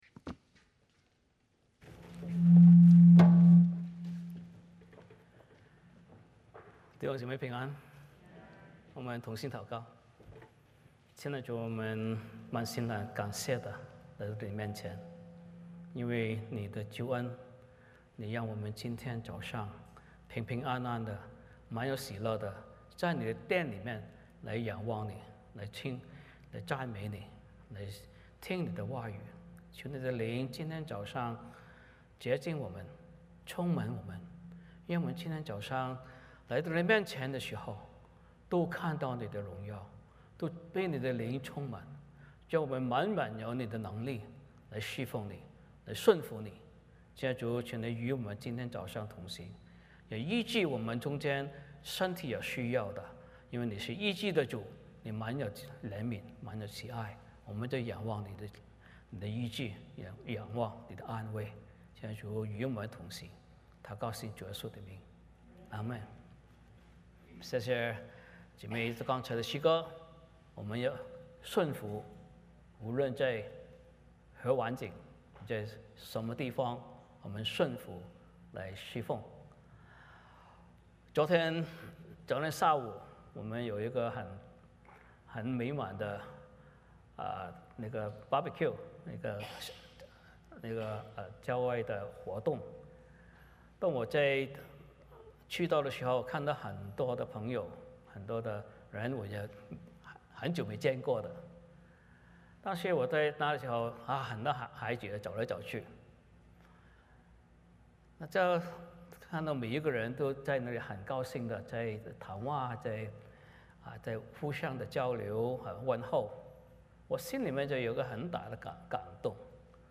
欢迎大家加入我们国语主日崇拜。
12-42 Service Type: 主日崇拜 欢迎大家加入我们国语主日崇拜。